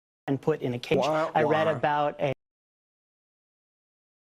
Womp Womp Sound Button: Unblocked Meme Soundboard